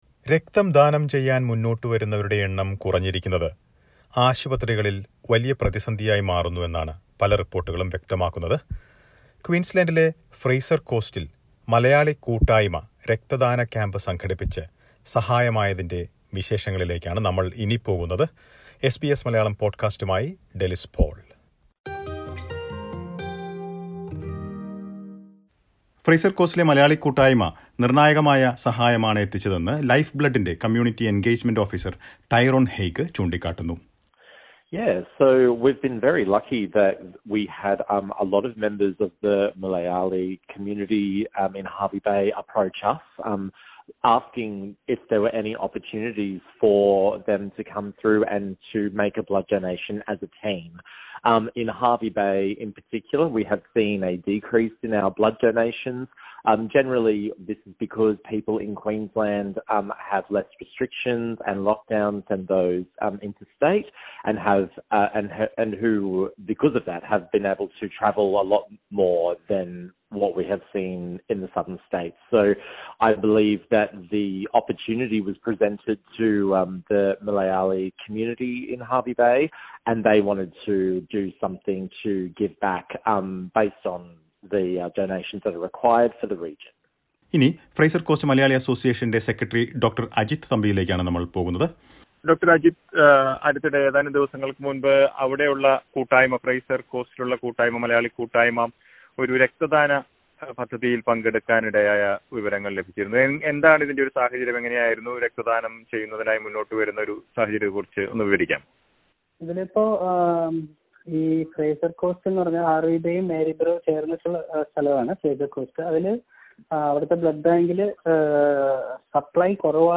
Listen to a report about Blood donation camp organised by Fraser Coast Malayalee Community.